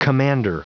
Prononciation du mot commander en anglais (fichier audio)
Prononciation du mot : commander